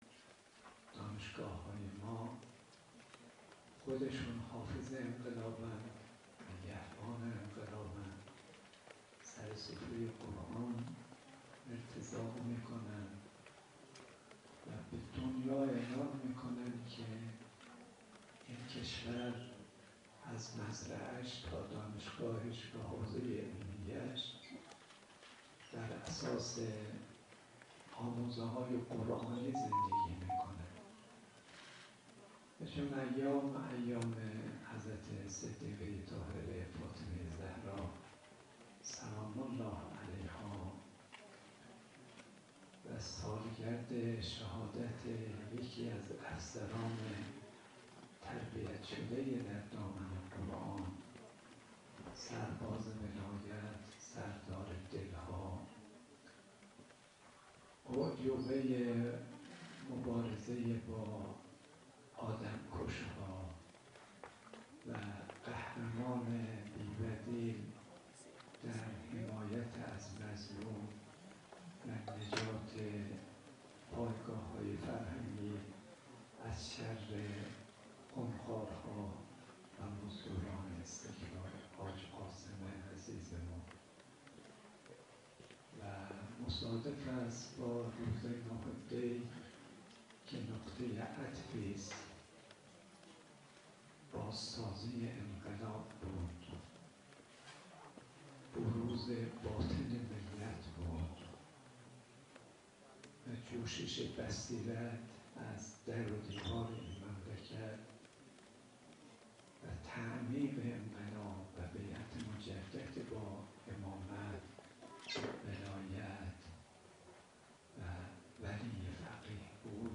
اختتامیه سی و ششمین جشنواره قرآن و عترت وزارت علوم برگزار شد + صوت
در بخشی از این مراسم، حجت‌الاسلام والمسلمین کاظم صدیقی، امام جمعه موقت تهران با بیان اینکه قرآن، اعجازِ همیشه اعجاز است، عنوان کرد: از روز نزول تا قیامت مبارز طلبیده و اعلام کرده که هیچ کسی مثل یک سوره از قرآن را نخواهد توانست عرضه کند، یک مقداری از اعجاز قرآن مکشوف و بخش‌های زیادی نیز هنوز جزو اسرار است و به مرور زمان کشف خواهد شد.